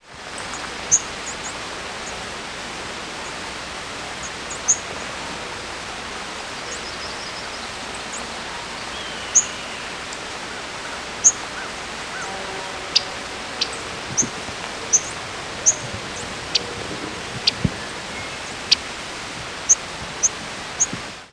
Yellow-rumped Warbler diurnal flight calls
Chips and flight calls from flying bird with Chipping Sparrow, Blue Jay, and American Crow calling and Mourning Dove and Hermit Thrush singing in the background.